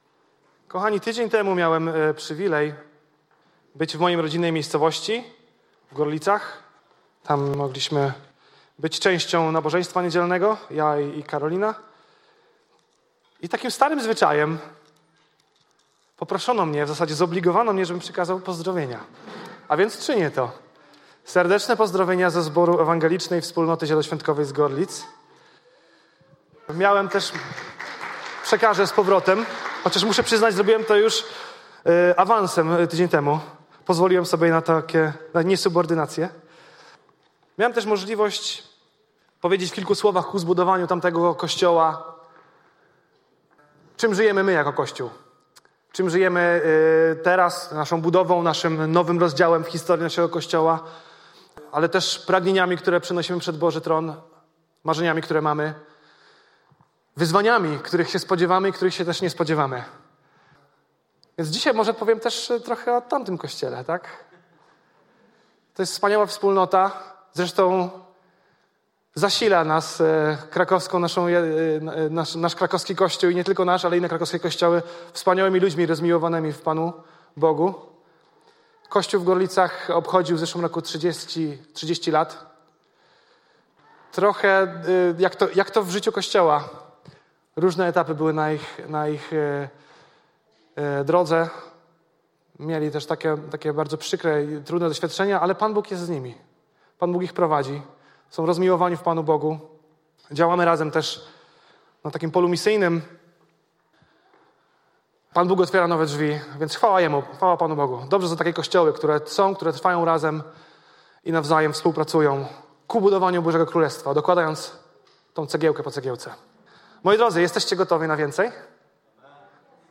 Pytania do przemyślenia po kazaniu: